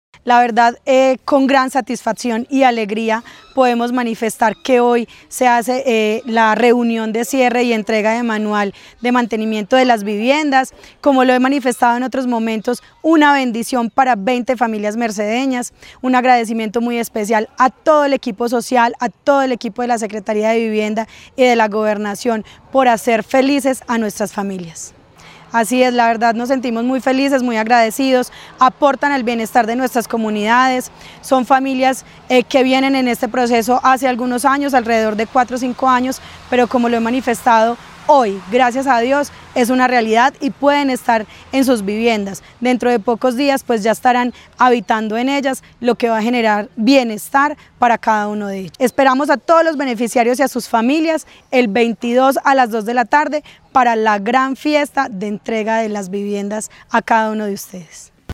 Yeni Fernanda Henao Dávila, alcaldesa de La Merced